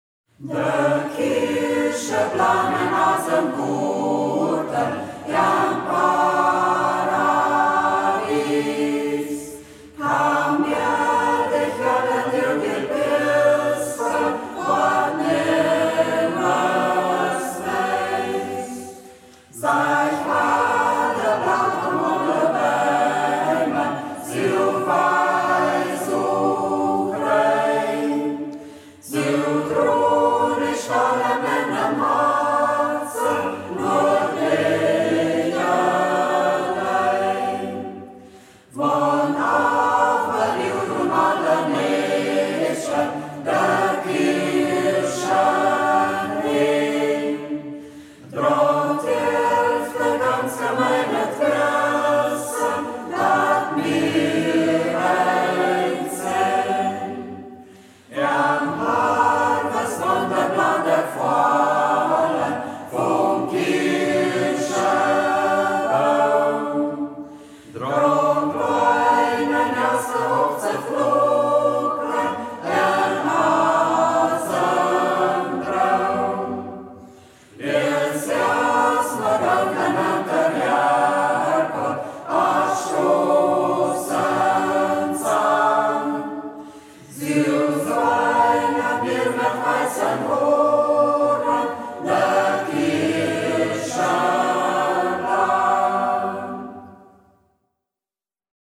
Singkreis Kampestweinkel • Ortsmundart: Braller